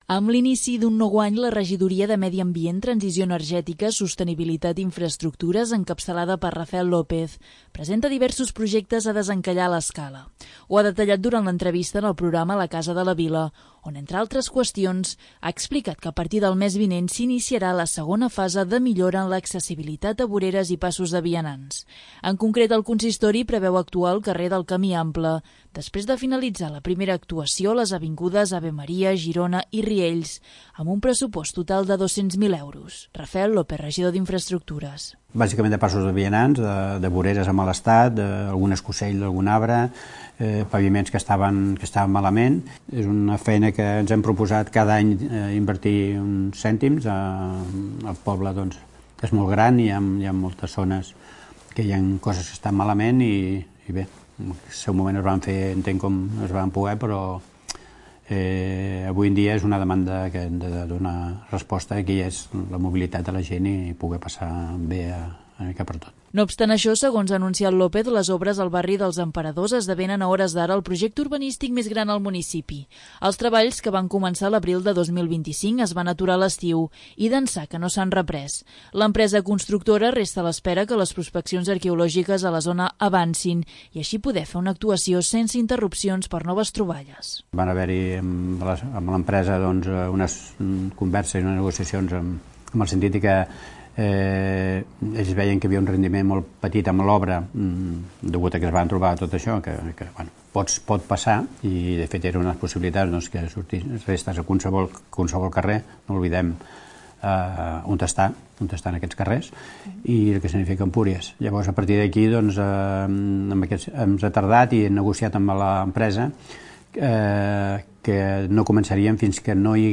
Ho ha detallat durant l'entrevista en el programa 'La Casa de la Vila', on entre altres qüestions, ha explicat que a partir del mes vinent s'iniciarà la segona fase de millora en l'accessibilitat a voreres i passos de vianants.